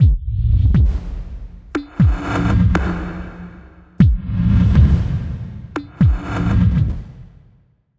Abstract Rhythm 44.wav